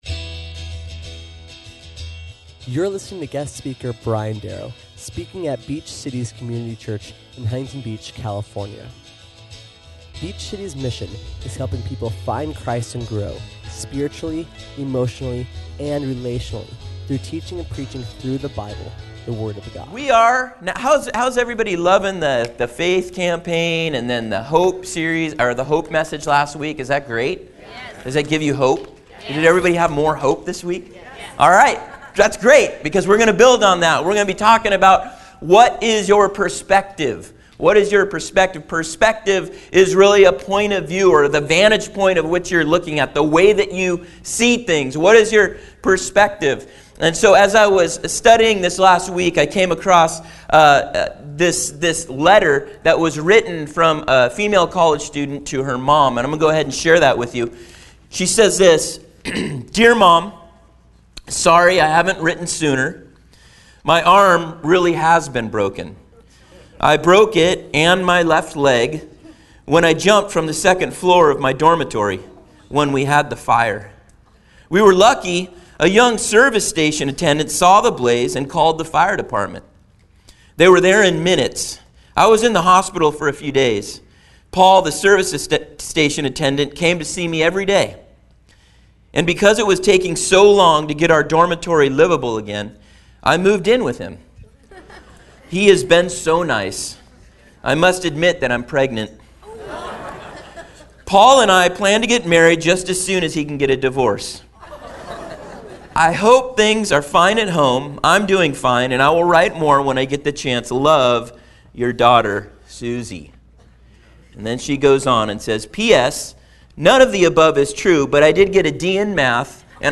We learn that in order to do this, we need to see what God sees. Listen or watch as we learn three things that God sees and how that shapes our perspective. SERMON AUDIO: SERMON NOTES: